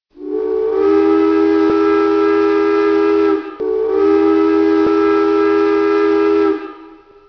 SouFt whistle two long.wav